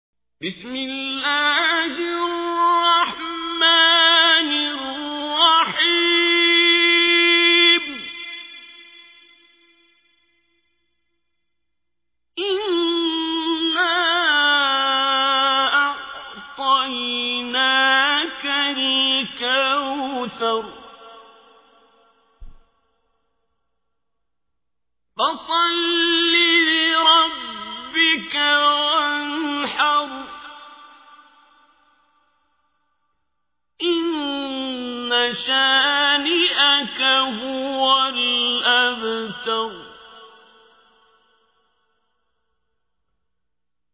تحميل : 108. سورة الكوثر / القارئ عبد الباسط عبد الصمد / القرآن الكريم / موقع يا حسين